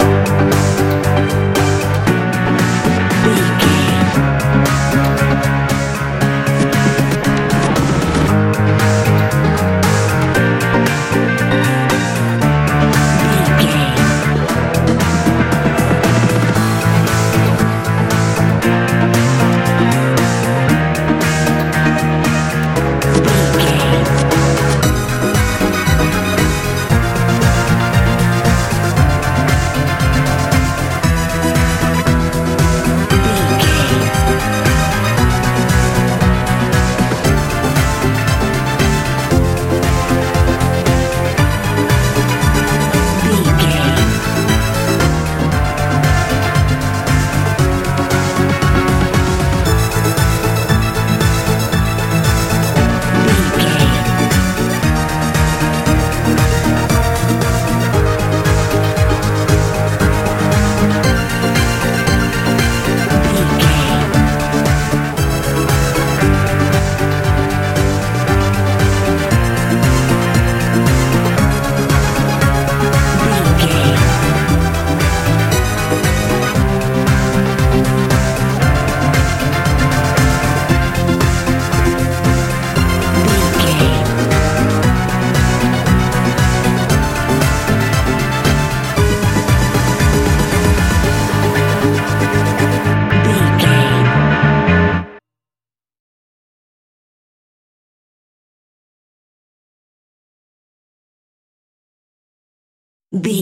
modern dance feel
Ionian/Major
D
magical
mystical
synthesiser
bass guitar
electric piano
80s
strange
heavy